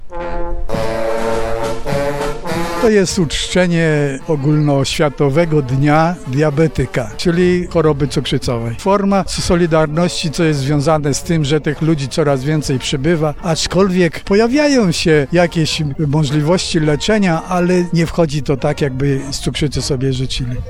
Najpierw była msza święta w intencji cukrzyków w Kolegiacie Najświętszej Marii Panny, a potem happening na Rynku Staromiejskim.
– mówi jeden z uczestników happeningu